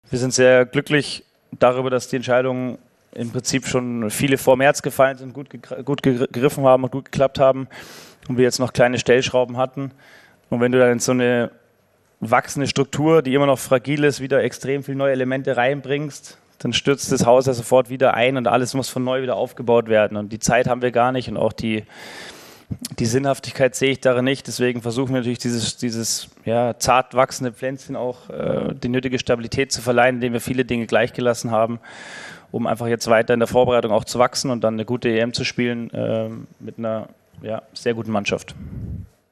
Nagelsmann sagte in der Pressekonferenz, dass es ihm nicht leicht gefallen sei, den Spielern abzusagen.
Das sagt Nagelsmann zur Kader-Entscheidung: